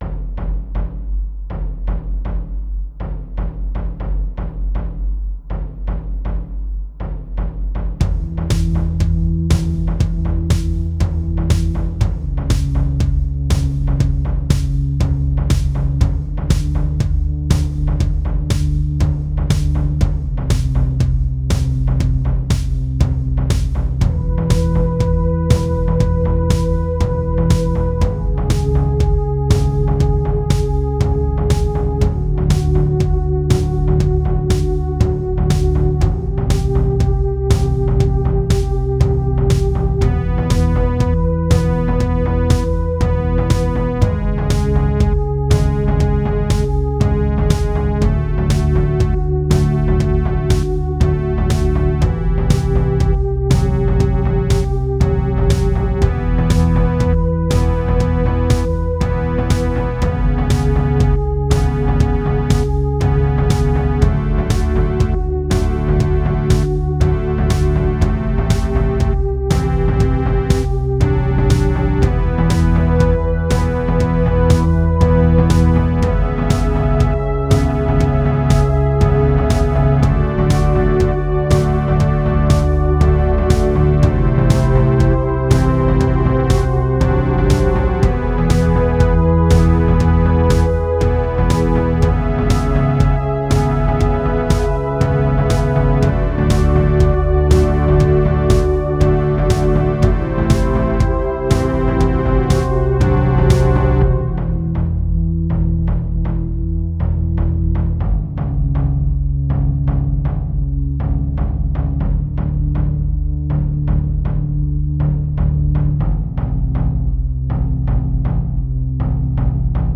A four-part suite